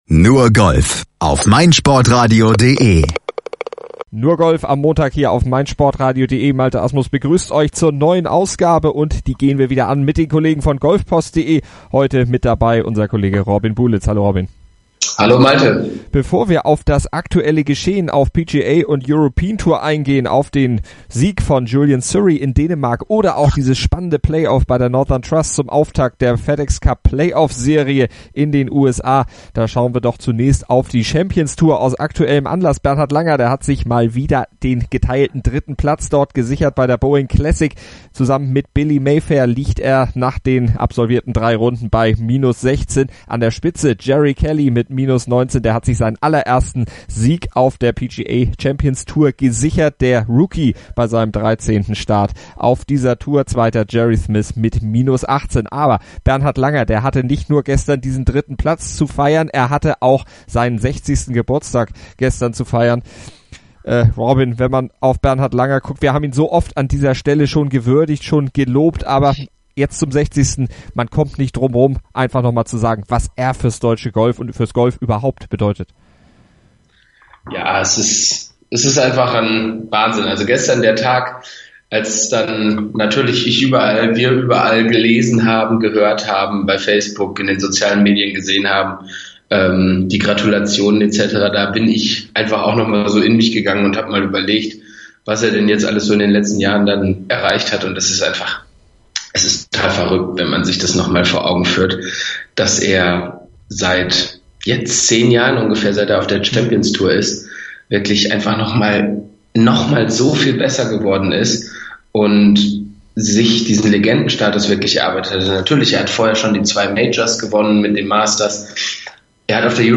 Marcel Siem holte dort die nächste Top Ten-Platzierung und nimmt im Interview Stellung zu seinem Turnier und gibt einen Ausblick auf den Saisonendspurt mit seinen "Lieblingsturnieren".